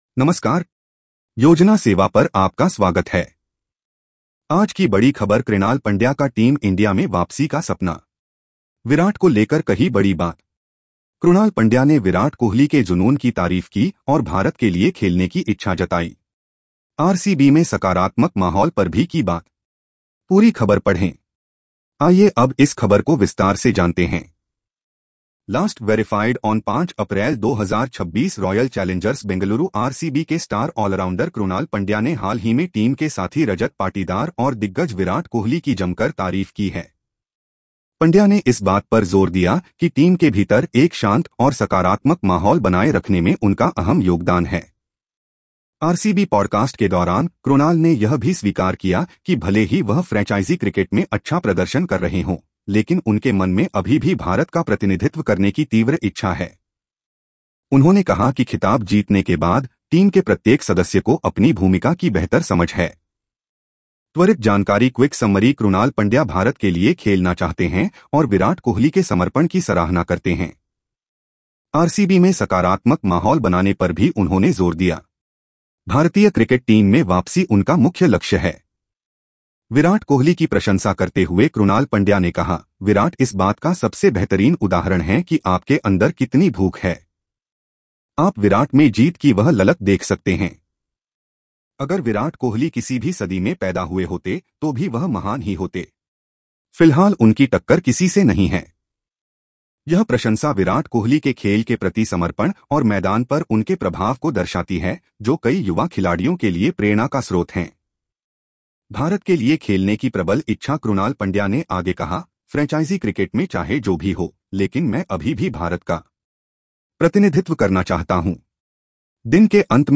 🎧 इस खबर को सुनें (AI Audio):